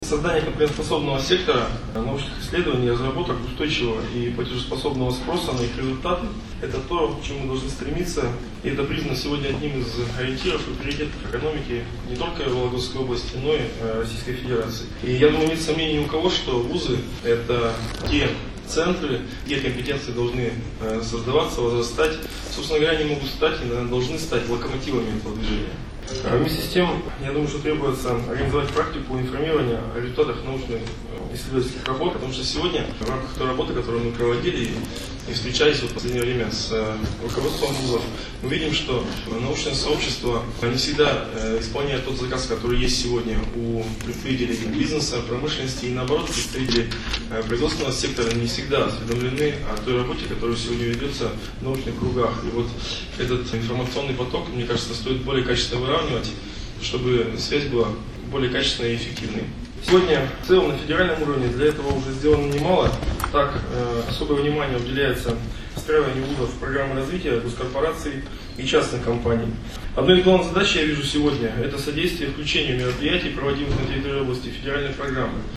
В преддверие дня науки в Вологде обсудили проблемы данной сферы. Заседание прошло в стенах регионального Правительства в четверг, 6 февраля.
В своей приветственной речи некоторые проблемы и задачи, стоящие перед данной сферой, обозначил заместитель губернатора области Алексей Кожевников.